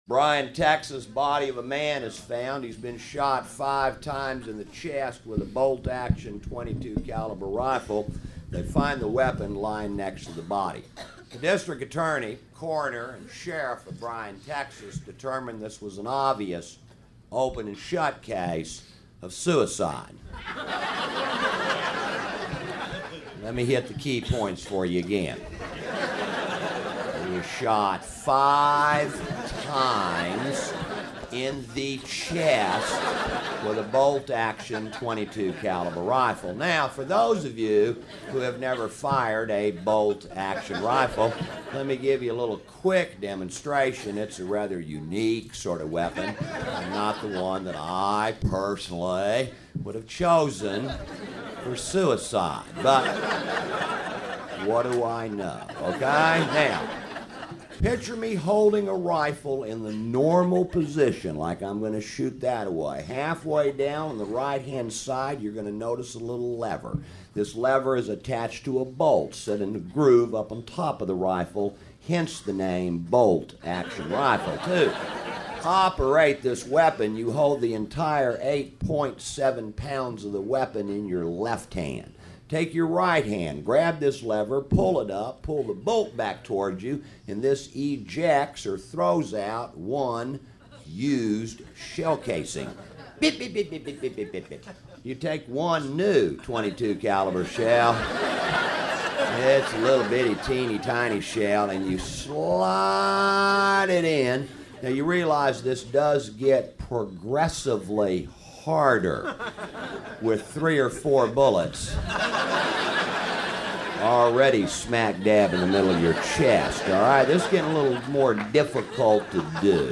GUEST COMICS